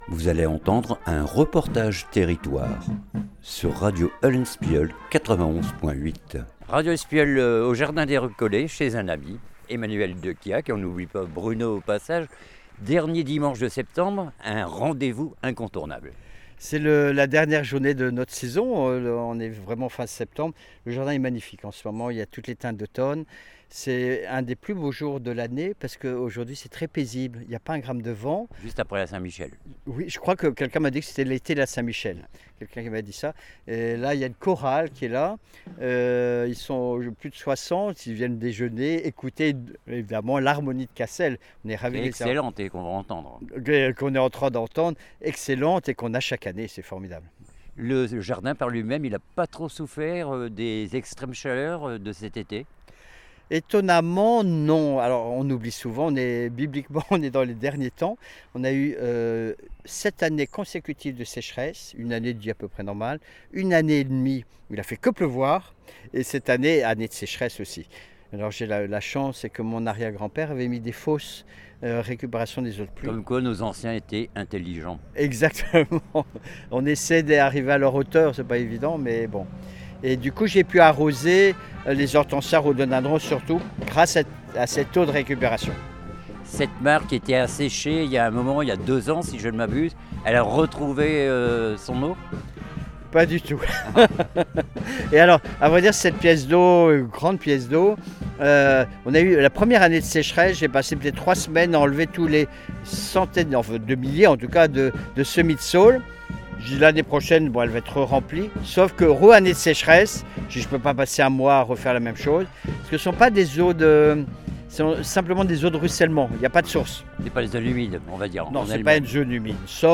REPORTAGE TERRITOIRE FETE DE LA POMME LES RECOLLETS CASSEL
UN DIMANCHE AU JARDIN DES RECOLLETS ....